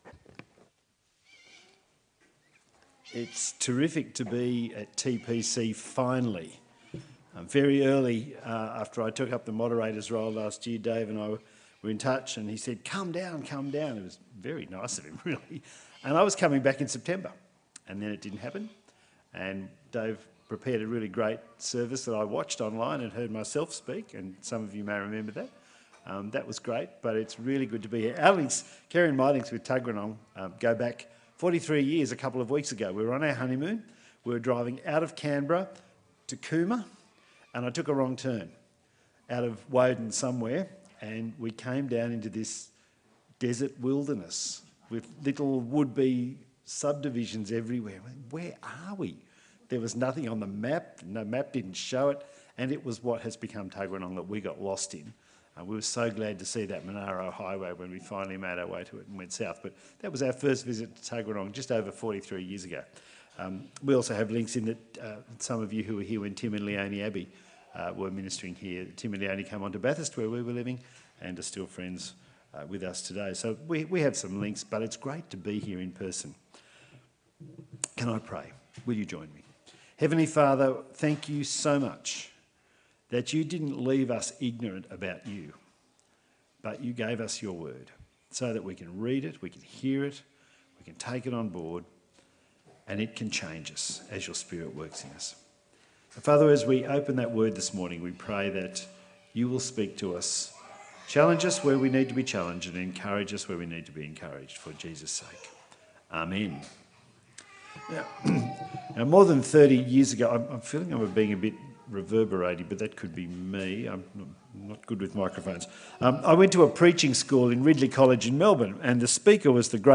Passage: Hebrews 3:7-4:12 Service Type: Sunday Service